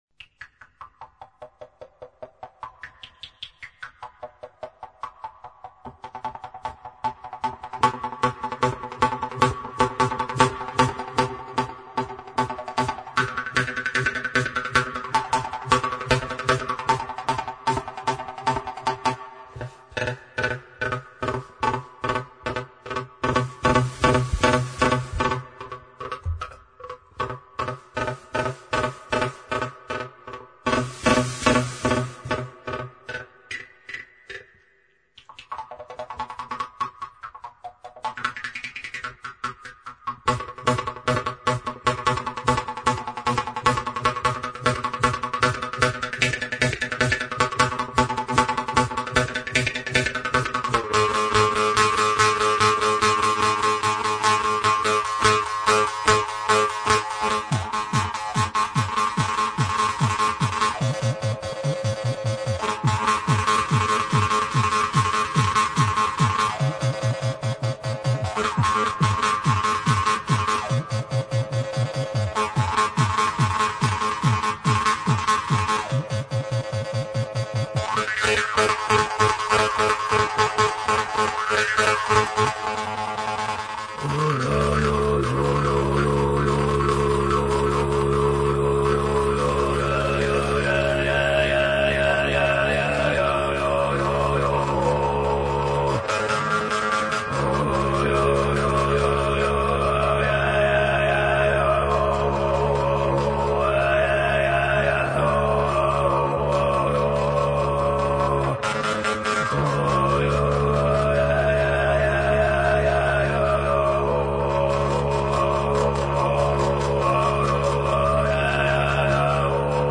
Инструментальные пьесы [9]
виртуозная пьеса для шанкобыза
горловое пение, Шанкобыз